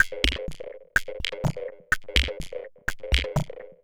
tx_perc_125_clockwerk1.wav